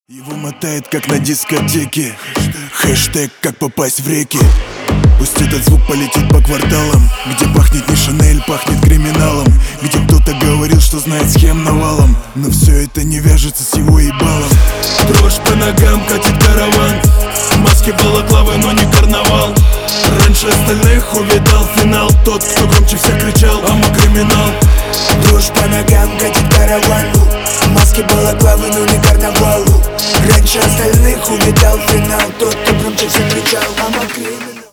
Внимание Ненормативная лексика!
Рэп и Хип Хоп
клубные # громкие